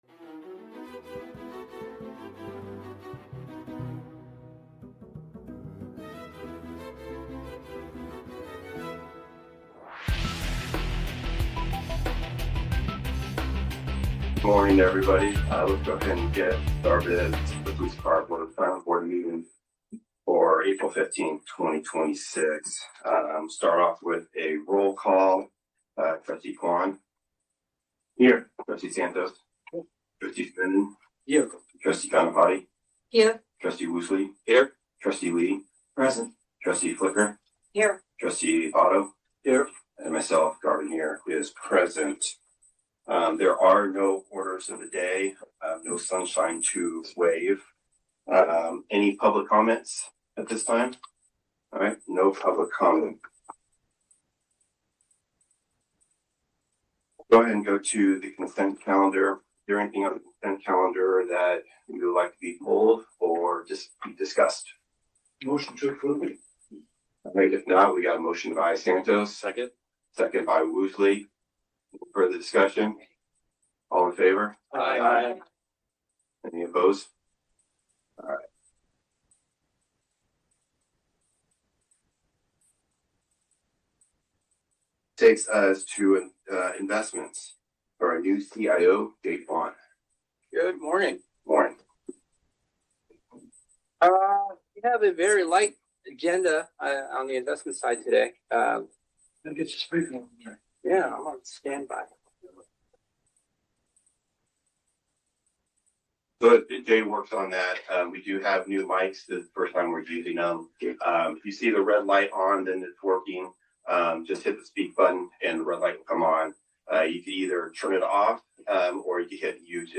You'll hear authentic audio capturing the voices of city officials, community leaders, and residents as they grapple with the local issues of the day. This podcast serves as an archival audio record, providing transparency and a direct line to the workings of local government without editorial polish.